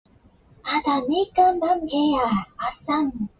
Play, download and share plague gibberish original sound button!!!!